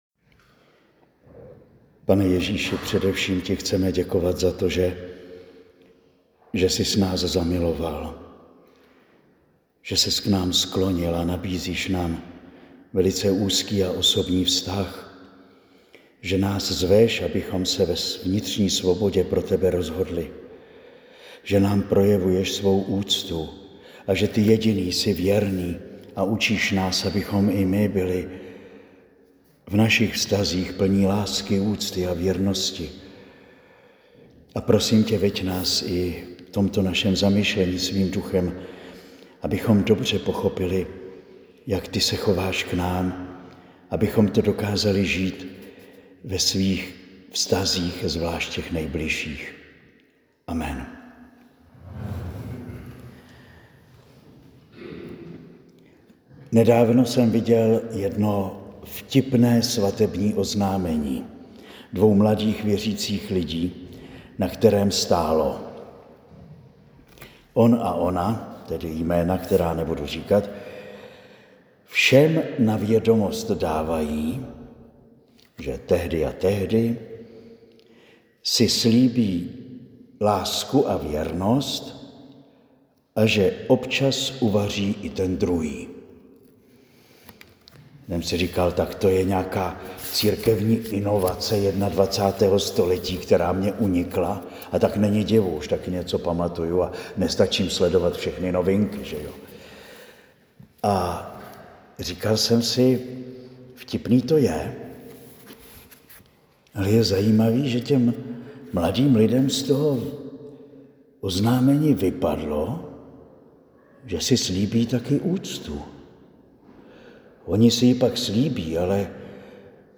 Promluva zazněla na pouti pro muže dne 6. 7. 2025 v Kostelním Vydří.